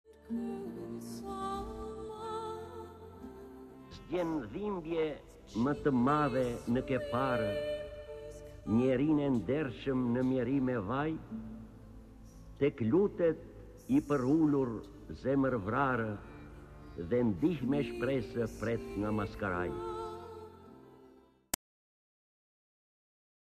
D. AGOLLI - PËRULJA E MADHE Lexuar nga D. Agolli KTHEHU...